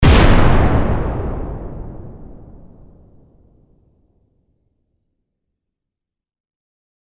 Tiếng Bom nổ một tiếng